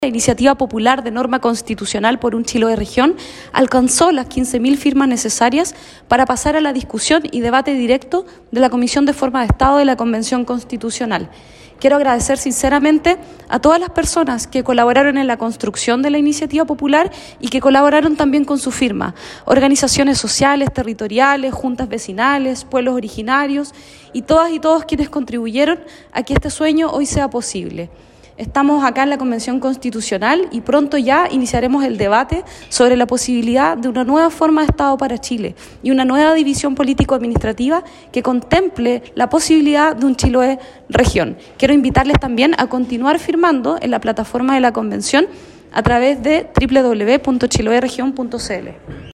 Un notable avance según todas las opiniones vertidas sobre esta propuesta, en la idea que Chiloé se convierta en una nueva región, tal como lo resumió la convencional Adriana Ampuero.